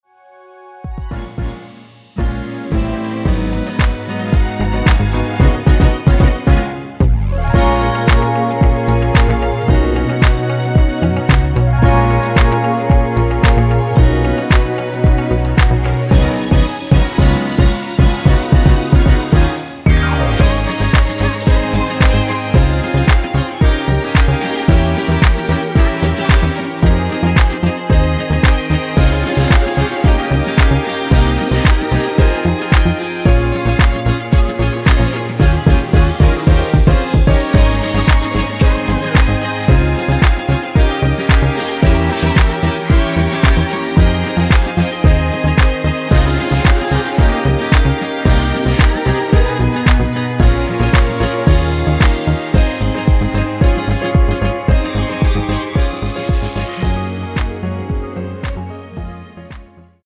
원키 코러스 포함된 MR 입니다.(미리듣기 참조)
F#
앞부분30초, 뒷부분30초씩 편집해서 올려 드리고 있습니다.
중간에 음이 끈어지고 다시 나오는 이유는